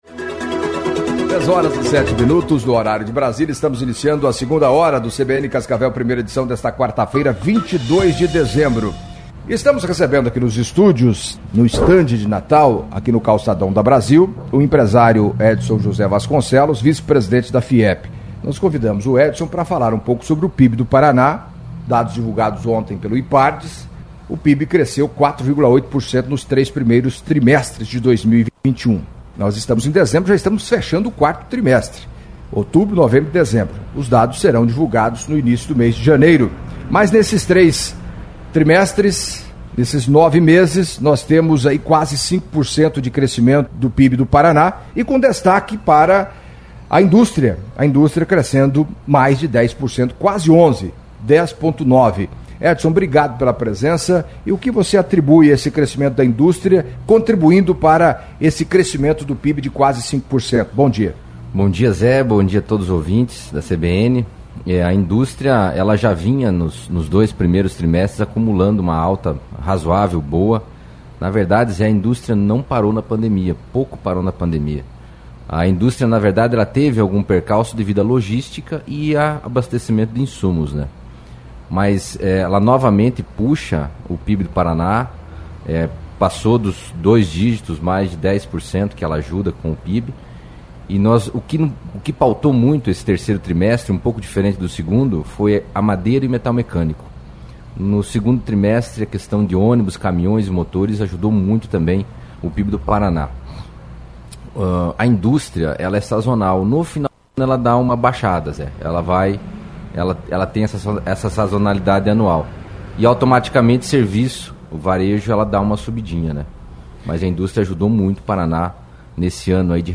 Em entrevista à CBN Cascavel nesta quarta-feira (22)